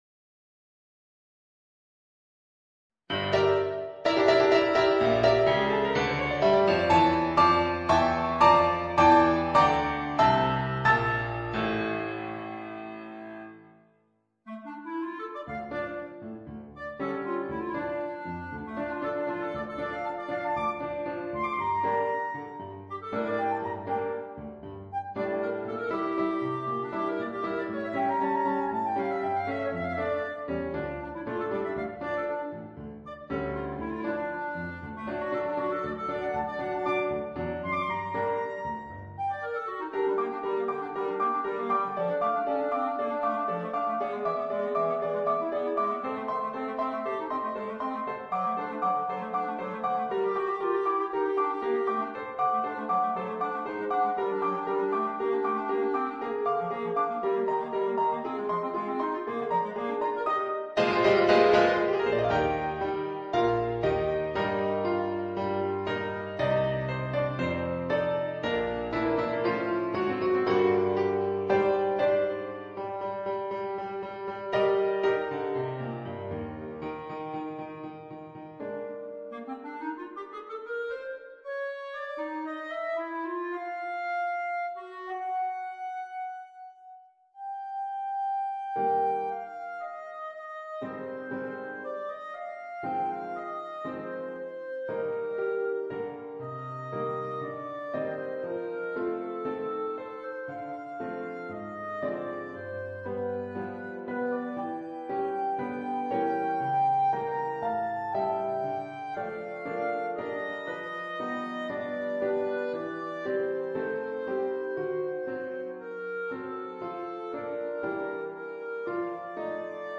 (Riduzione per clarinetto e pianoforte)